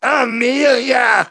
synthetic-wakewords
ovos-tts-plugin-deepponies_Demoman_en.wav